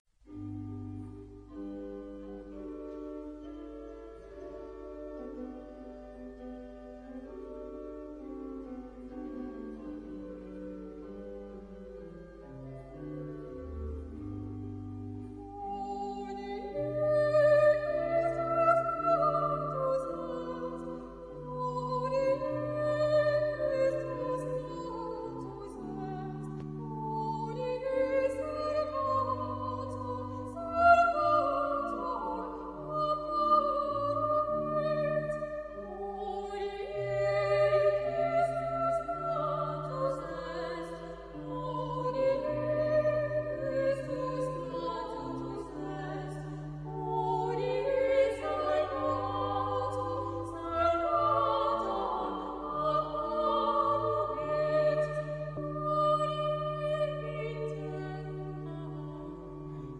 Genre-Style-Form: Christmas song ; Baroque ; Sacred ; Grand motet
Type of Choir: SA OR SS OR ST  (2 men OR women voices )
Instrumentation: Continuo  (2 instrumental part(s))
Instruments: Organ (1) ; Cello (1)
Tonality: G major